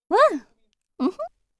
cheers2.wav